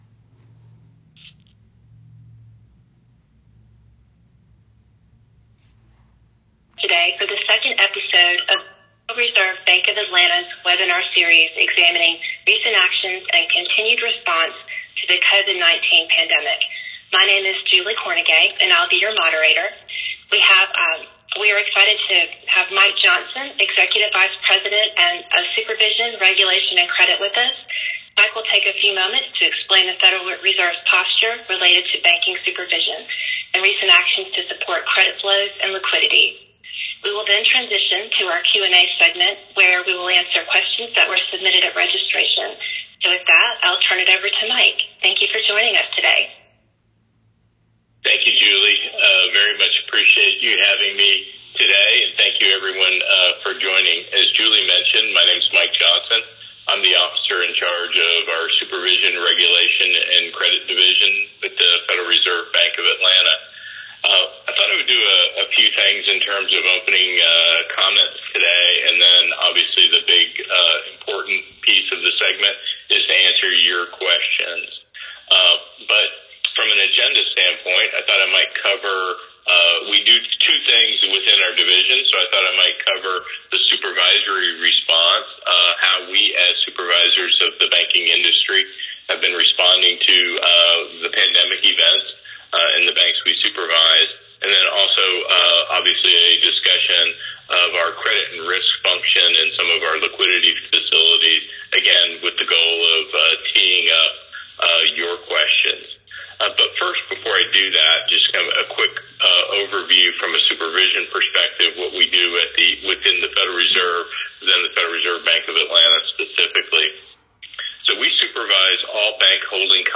Pandemic Response Webinar Series
We will then transition to our Q and A segment where we will answer questions that were submitted at registration.